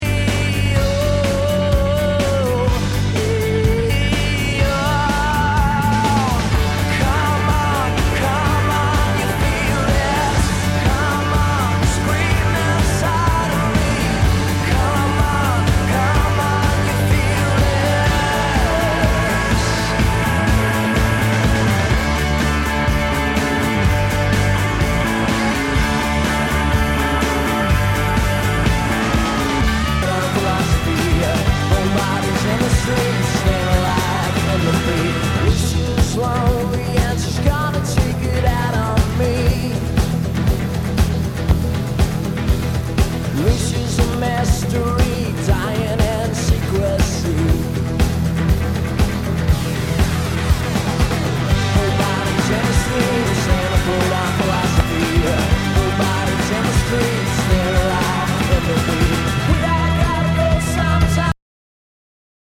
ROCK/POPS/INDIE